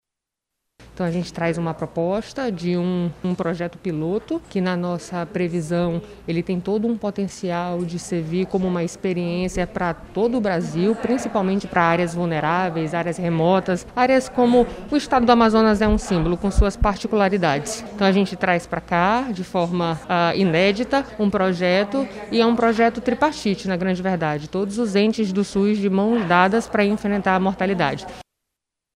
A diretora do Departamento de Ações Programáticas Estratégicas do Ministério da Saúde, Lana de Lourdes Aguiar, destacou a importância da assistência ao pré-natal para garantir o futuro do Brasil.
Sonora-Lana-de-Lourdes-Aguiar-diretora-do-Departamento-de-Acoes-Programaticas-Estrategicas-do-Ministerio-da-Saude.mp3